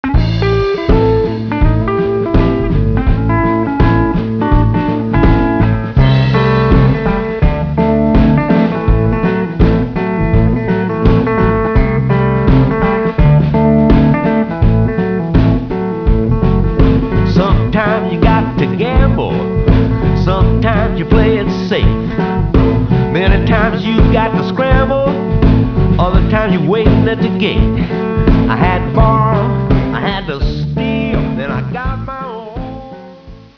Guitar, 3 string cigar-box guitar, vocals
Drums
Tracks 1-8 recorded at The Rogue Studios, Toronto, Ontario